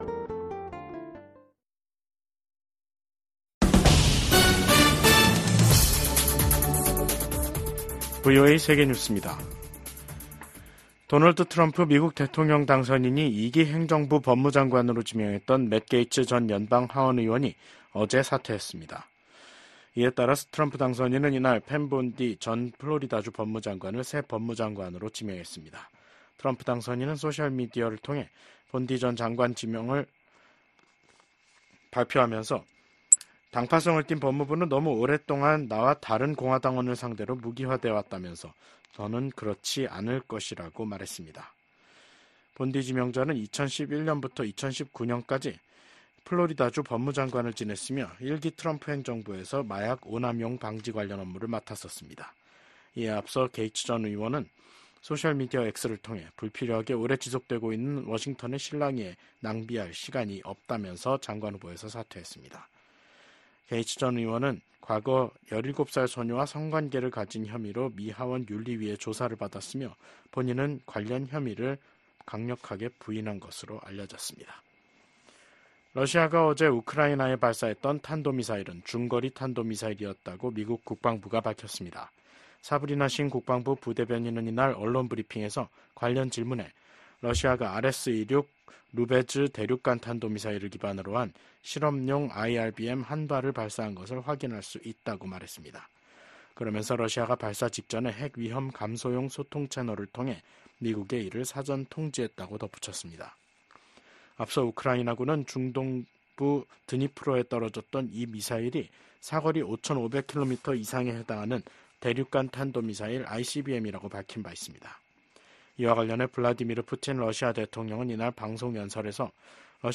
VOA 한국어 간판 뉴스 프로그램 '뉴스 투데이', 2024년 11월 22일 3부 방송입니다. 백악관이 북한군 참전을 우크라이나 전쟁 확전의 대표적 사례로 지목하고 러시아에 거듭 책임을 추궁했습니다.